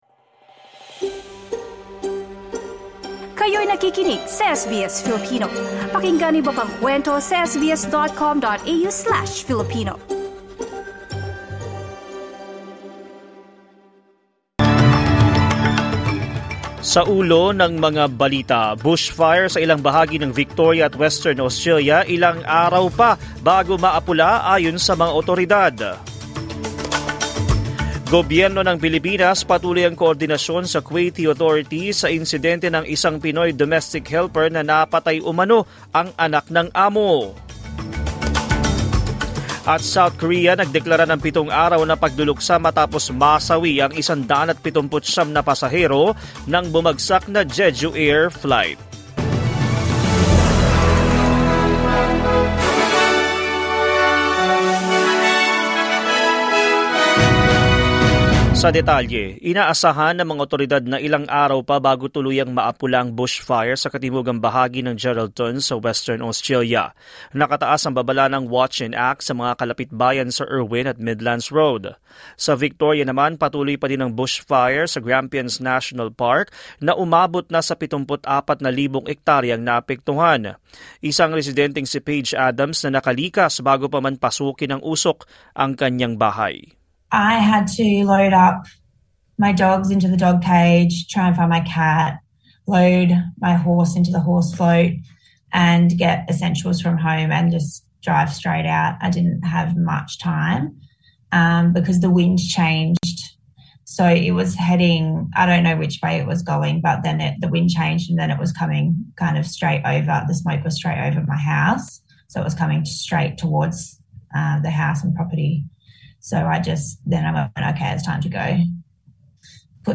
SBS News in Filipino, Monday 30 December 2024